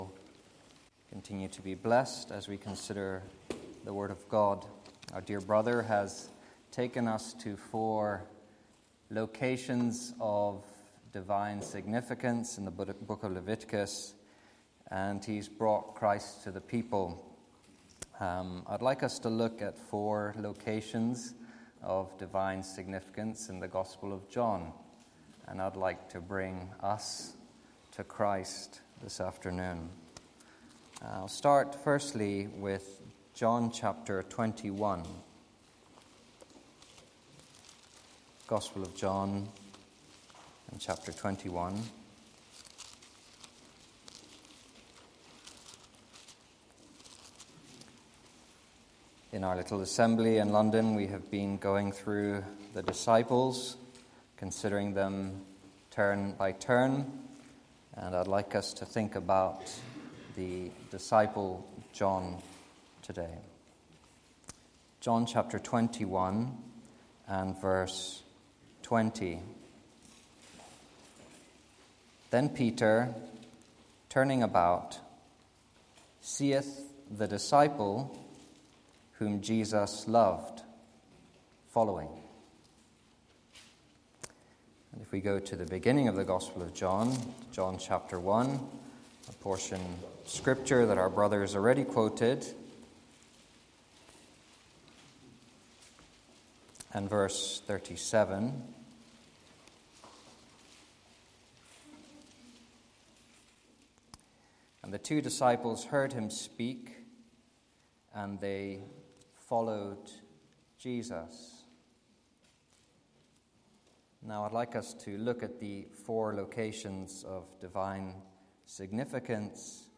2025 Easter Conference